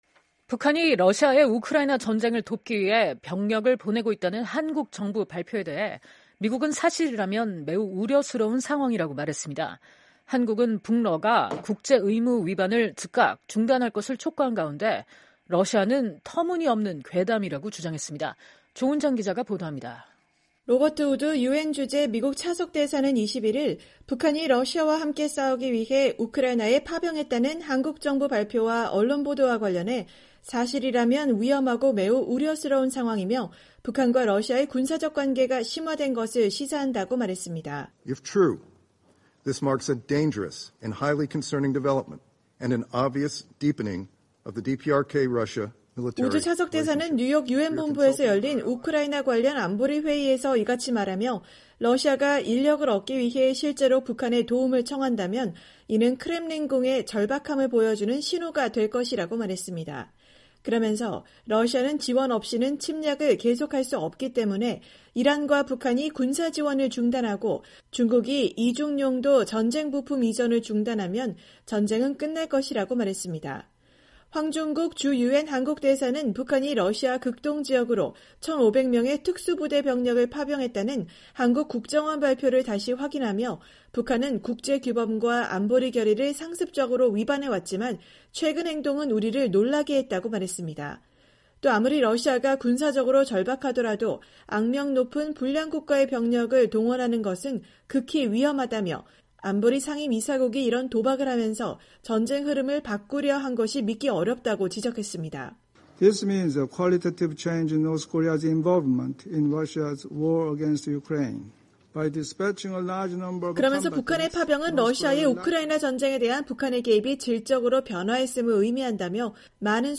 21일 미국 뉴욕 유엔본부에서 열린 우크라이나 관련 안보리 회의 (화면출처: UNTV)